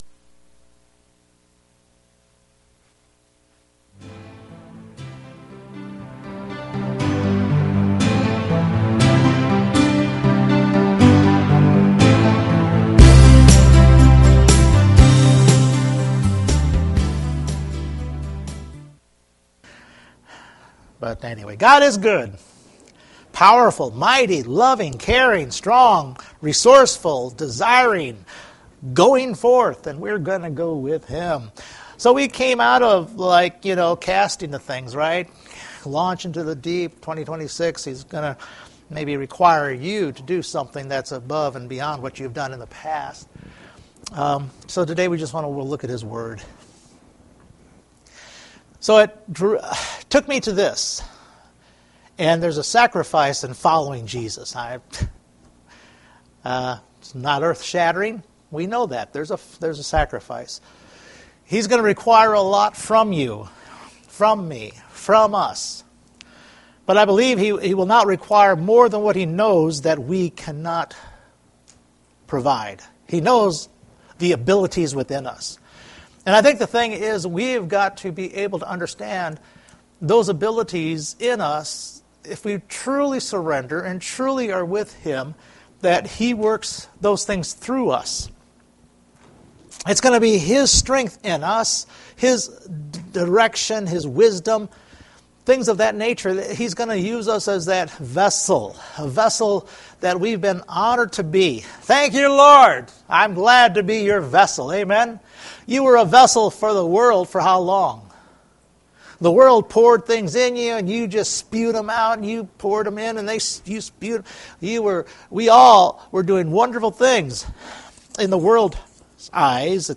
Luke 14:26 Service Type: Sunday Morning Luke 14:26 shares about setting apart the things of this world in following Jesus.